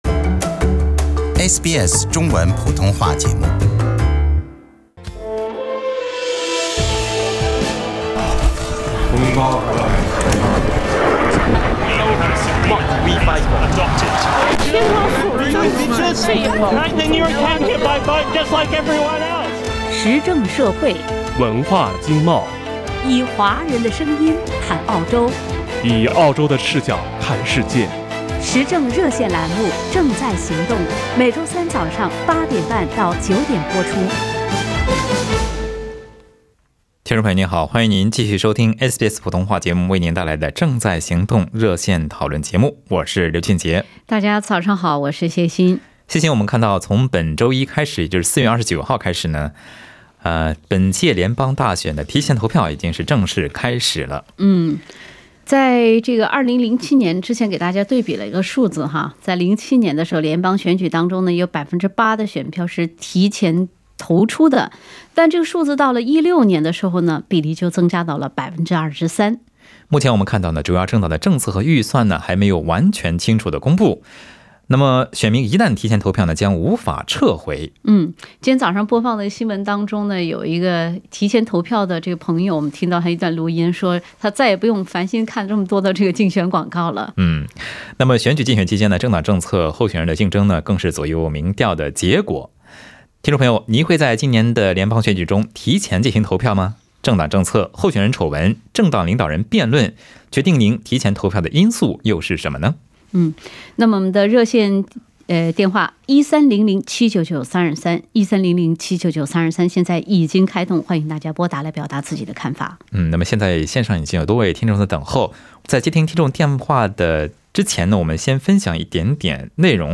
本期《正在行動》熱線節目討論中，聽眾朋友分享了自己的經驗和看法。